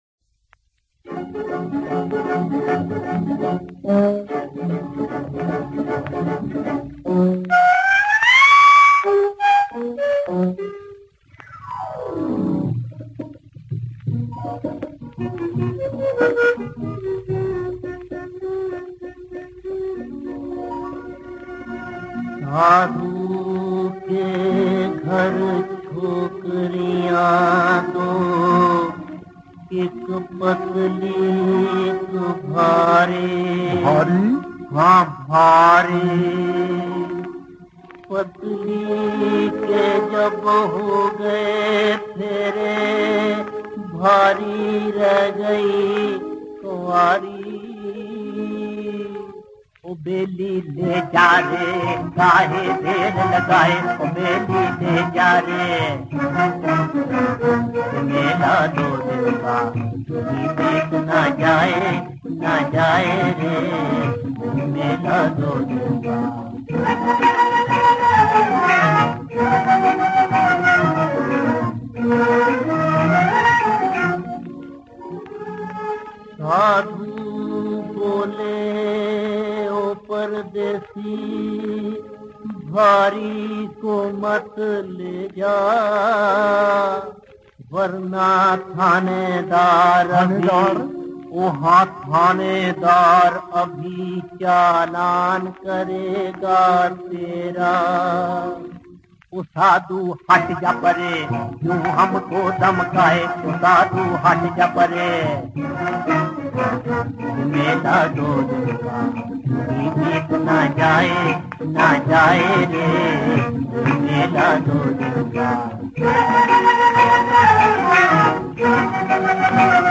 This is a light hearted song
Unknown voice